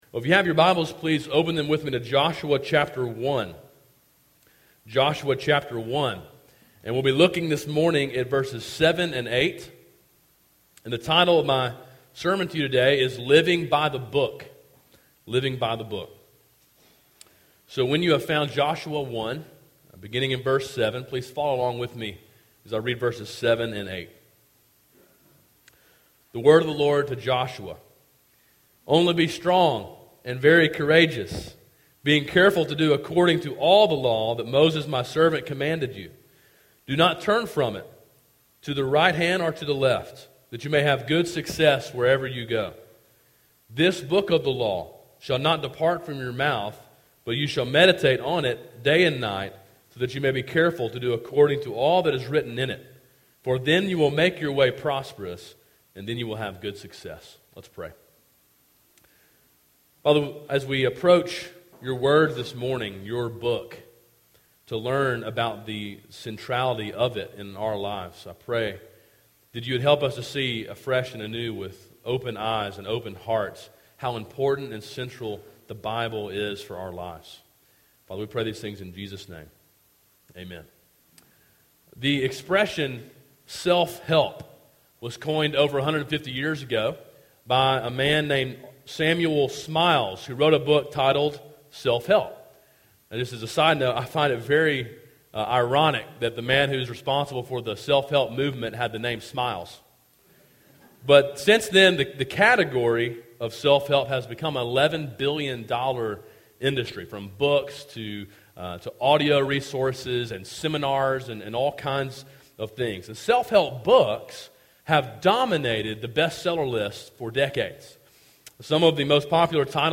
A sermon in a series on the book of Joshua.